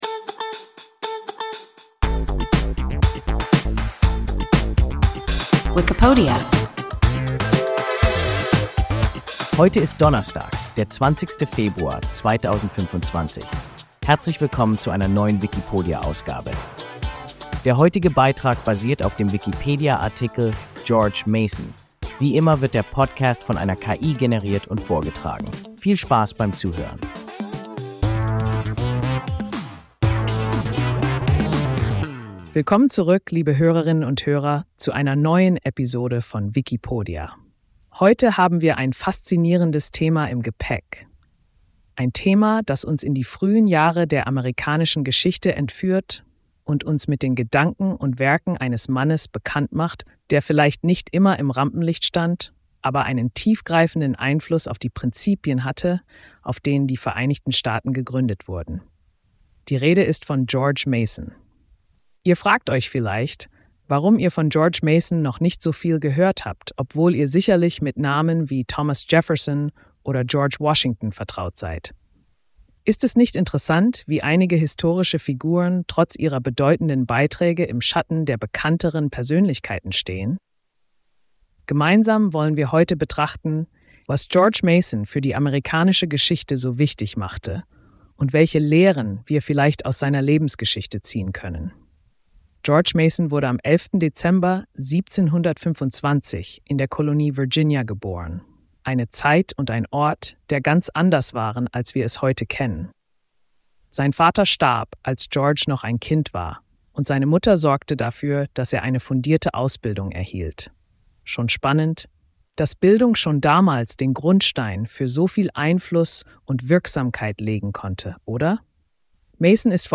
George Mason – WIKIPODIA – ein KI Podcast